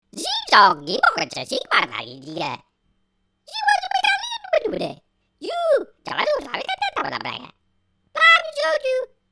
Alien Gepraat